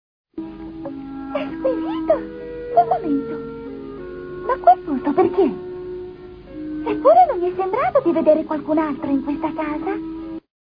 nel cartone animato "Sailor Moon e il mistero dei sogni", in cui doppia Diana.